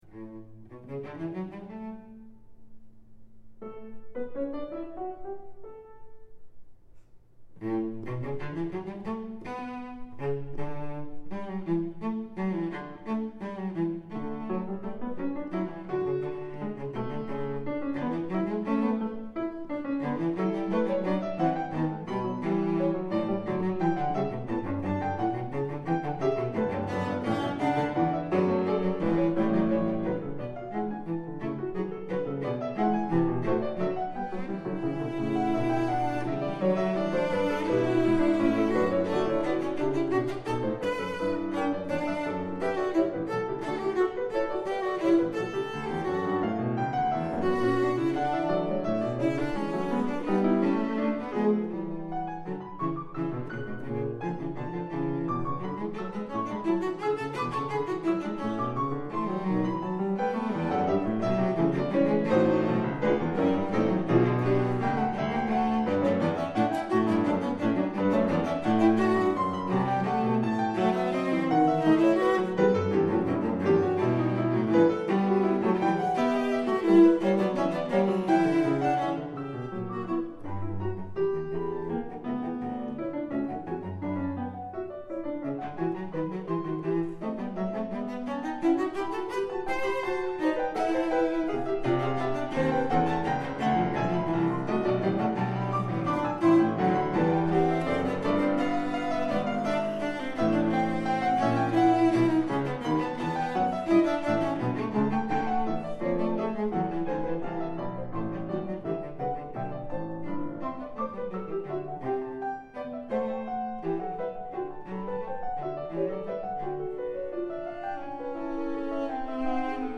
大提琴錄音/ 影像分享
小弟好一陣子沒空拍照，拿點用 PB 和 DV 錄的大提琴演奏和大家分享一下。